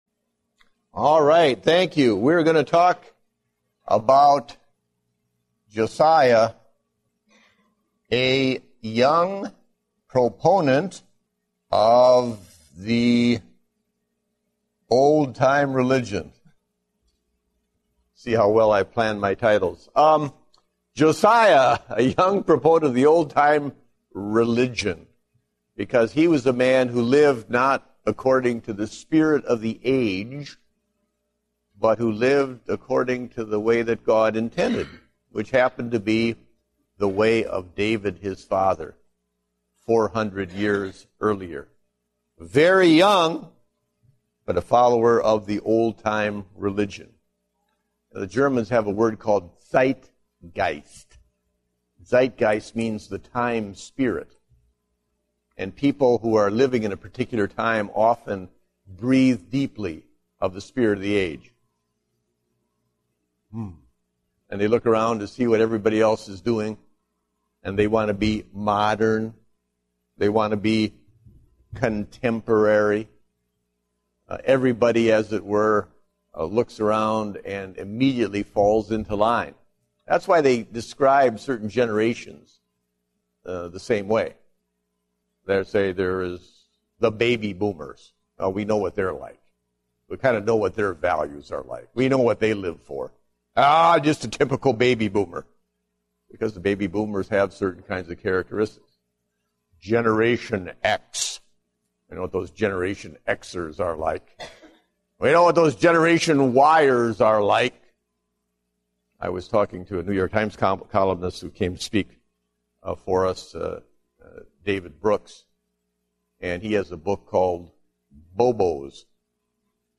Date: March 14, 2010 (Adult Sunday School)